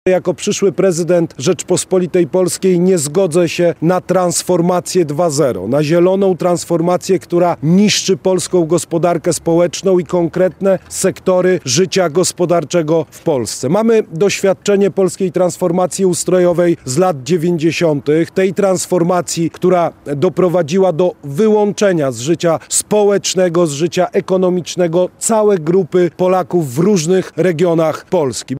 Sprzeciw wobec zielonej transformacji i wygaszania sektora węglowego w Polsce zadeklarował w Bogdance kandydat na prezydenta Karol Nawrocki. Wcześniej Nawrocki spotkał się z górnikami Lubelskiego Węgla.